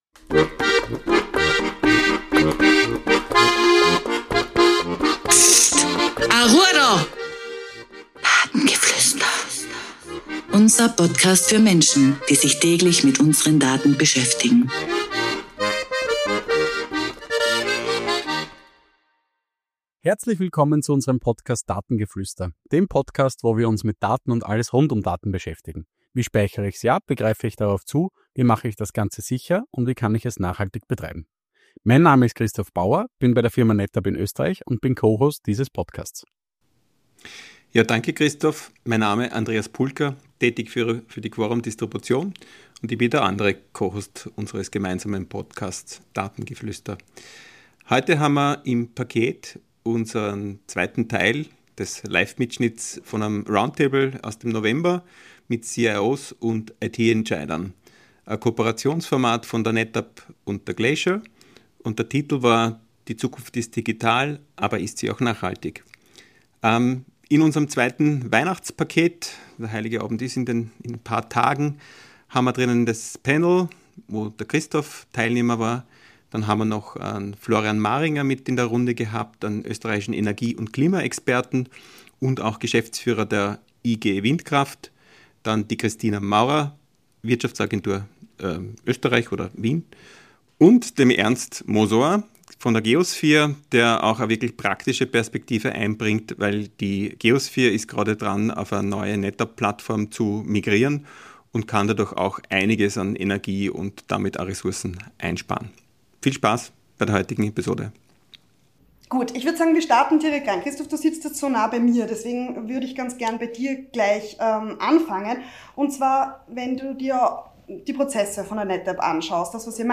In diesem Live-Mitschnitt unseres CIO-Roundtables dreht sich alles um die Frage, wie digital und nachhaltig unsere Zukunft wirklich ist – von Rechenzentren und Speichertechnologien über KI bis hin zur Energiewende und dem österreichischen Stromnetz.